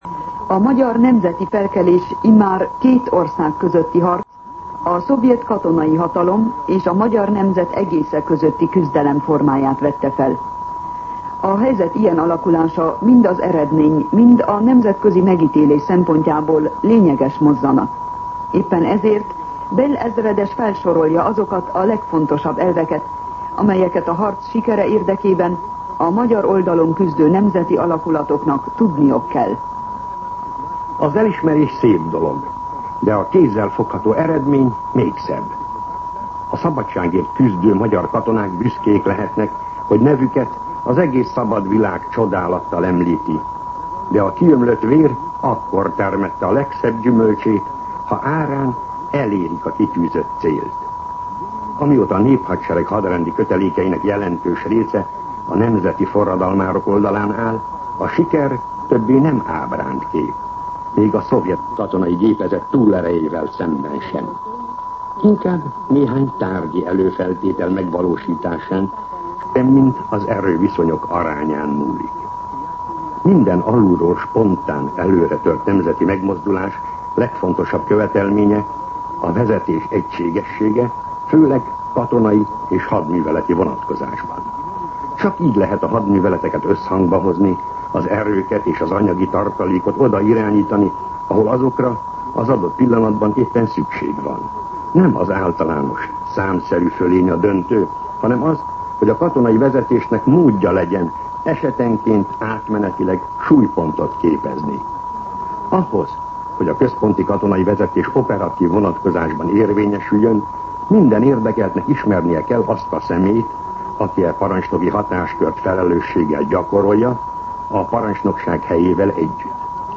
Katonapolitikai kommentár